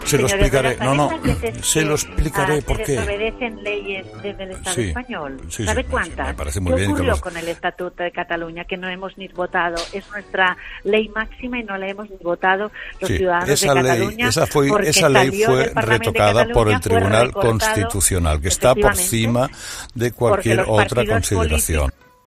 Declaraciones de Laura Borrás